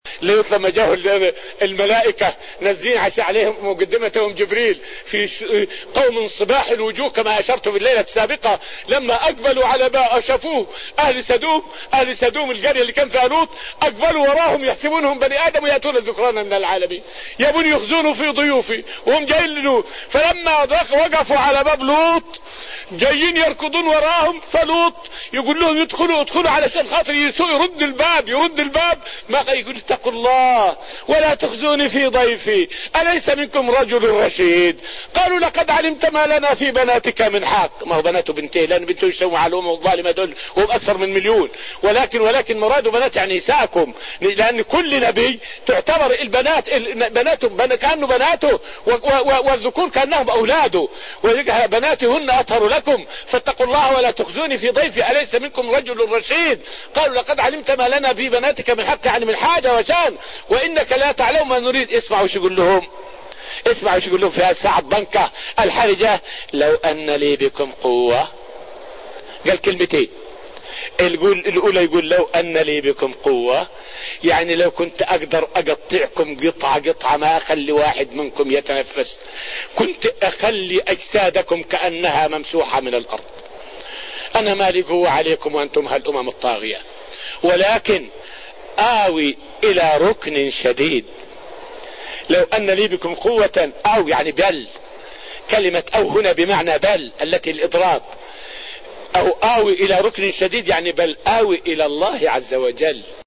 فائدة من الدرس الرابع من دروس تفسير سورة الأنفال والتي ألقيت في رحاب المسجد النبوي حول معنى قوله {هؤلاء بناتي هن أطهر لكم}.